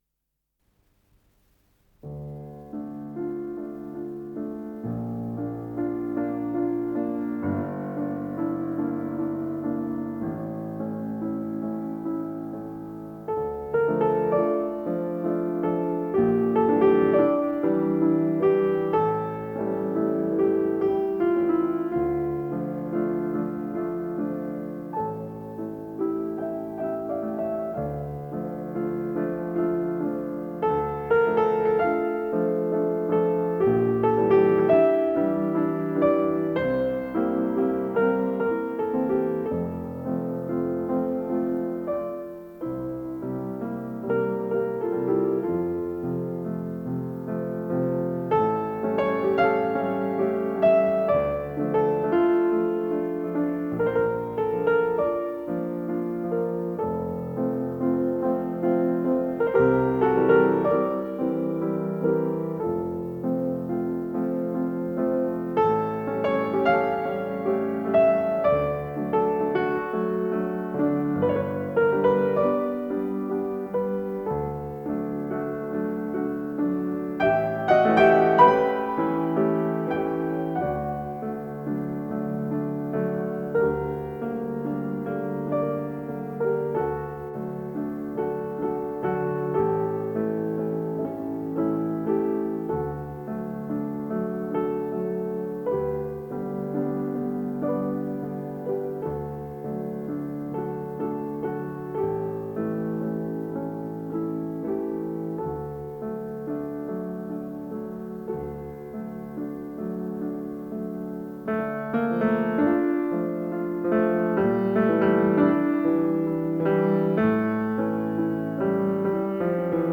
с профессиональной магнитной ленты
ПодзаголовокРе минор
фортепиано
ВариантДубль моно